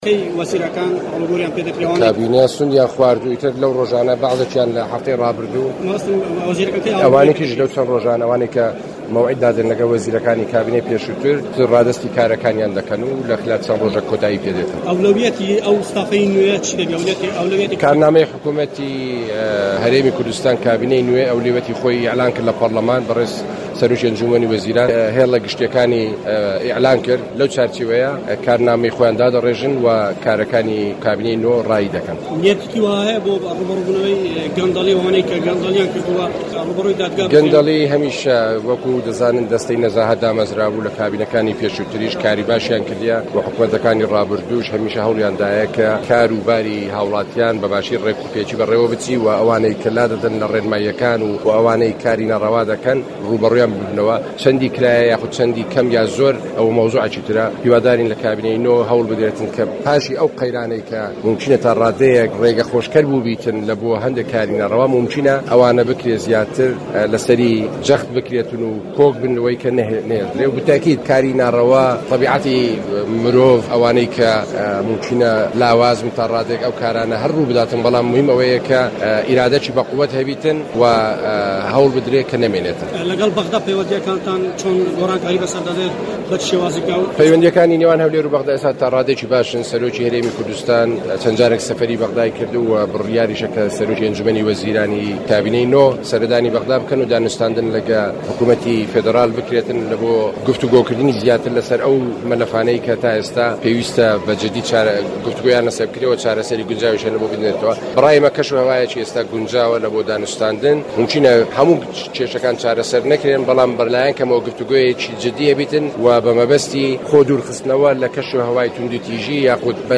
وتووێژ لەگەڵ سەفین دزەیی